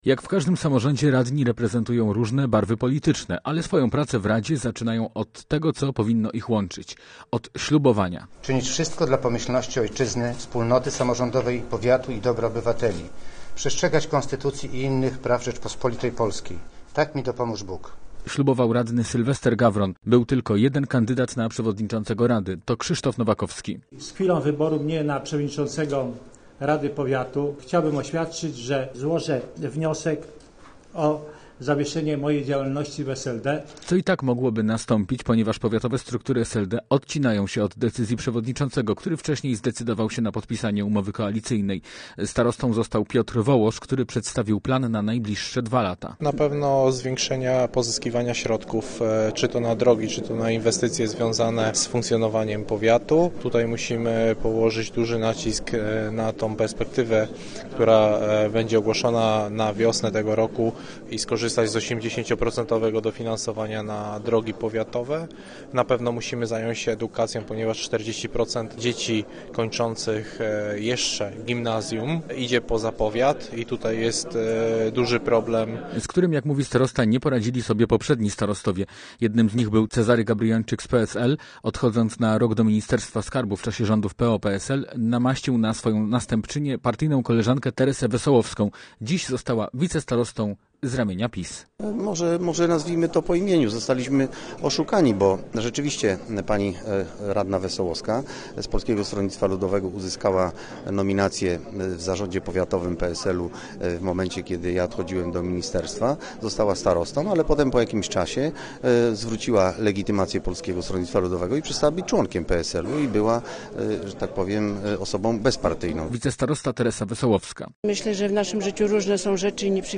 Posłuchaj relacji naszego reportera: Nazwa Plik Autor Nowe władze powiatu łaskiego audio (m4a) audio (oga) ZDJĘCIA, NAGRANIA WIDEO, WIĘCEJ INFORMACJI Z SIERADZA I REGIONU ZNAJDZIESZ W DZIALE “RADIO ŁÓDŹ NAD WARTĄ”.